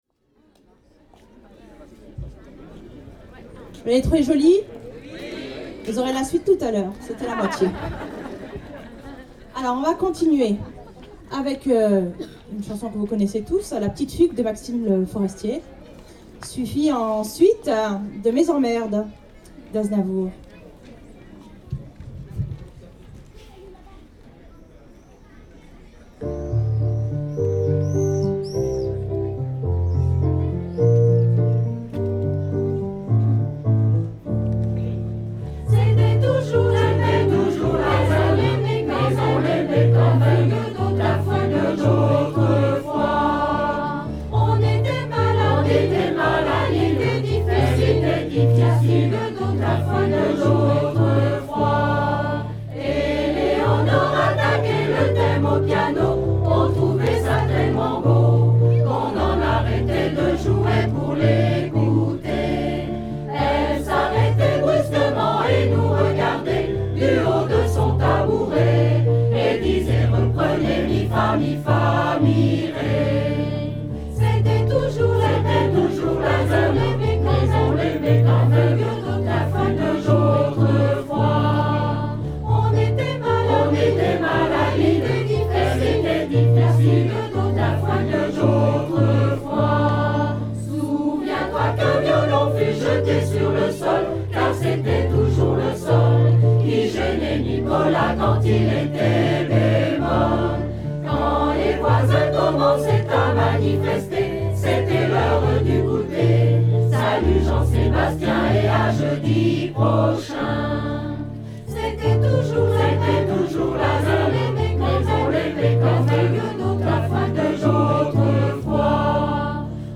Fête de la musique 2014